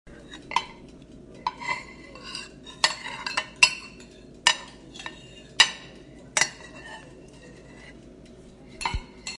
Download Kitchen sound effect for free.
Kitchen